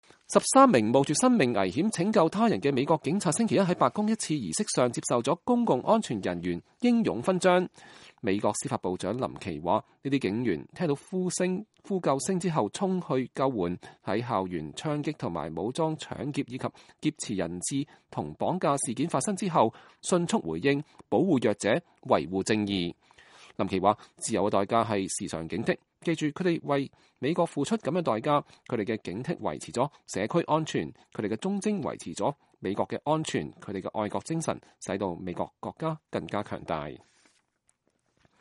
13名冒著生命危險拯救他人的美國警察星期一在白宮的一次儀式上接受了”公共安全人員英勇勳章”。